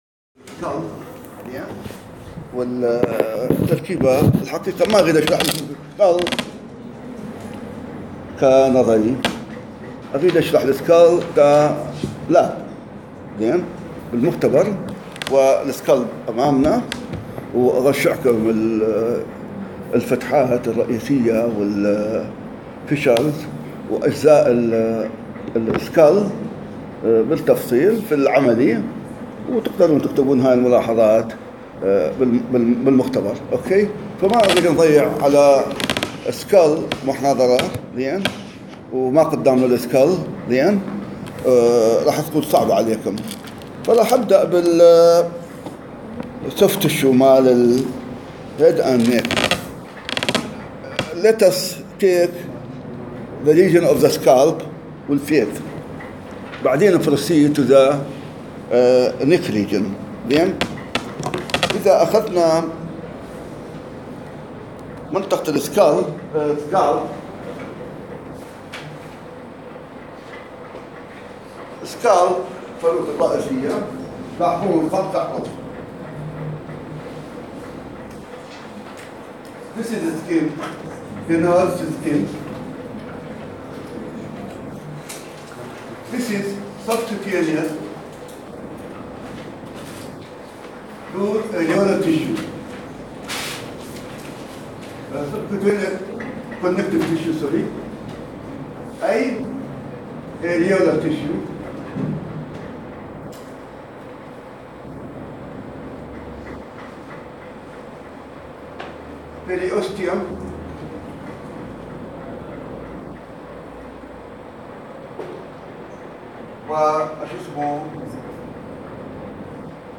التشريح > محاضرة رقم 1 بتاريخ 2015-10-05